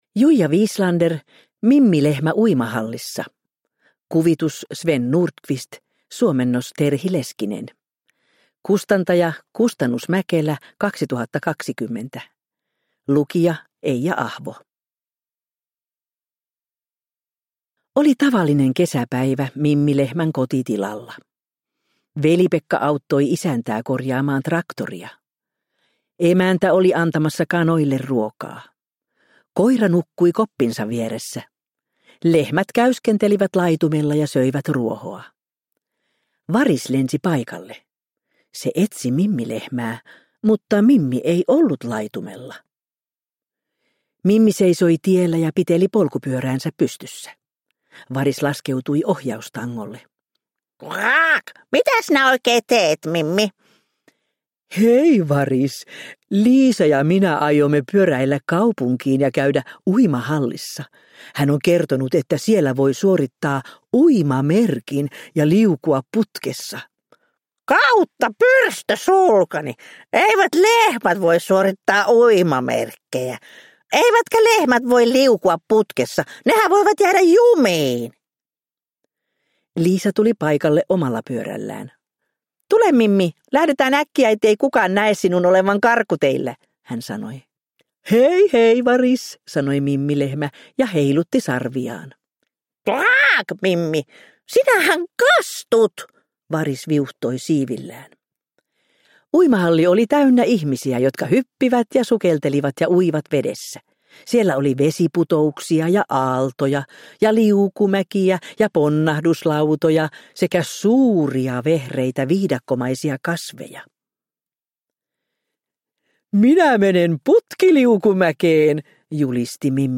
Mimmi Lehmä uimahallissa – Ljudbok – Laddas ner